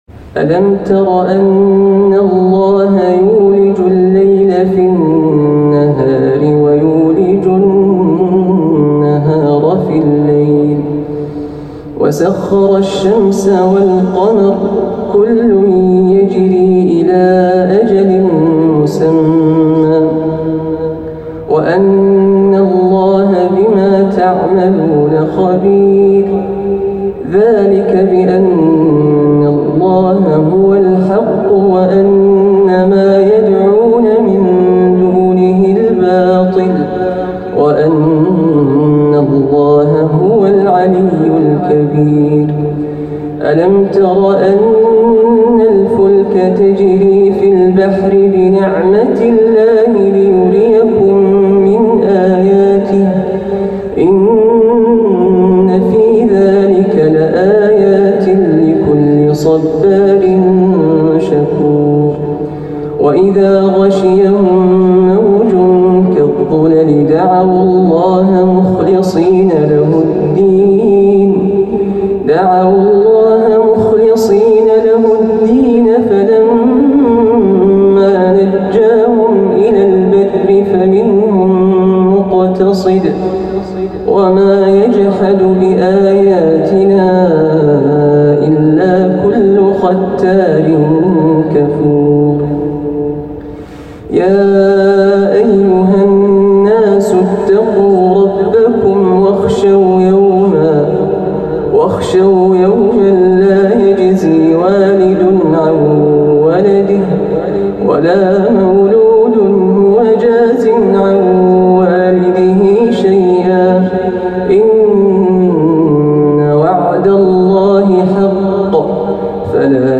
تلاوة خاشعة من أواخر سورة لقمان
ما شاء الله تلاوة خاشعة